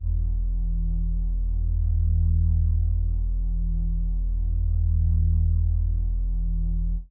OZ - 808 1.wav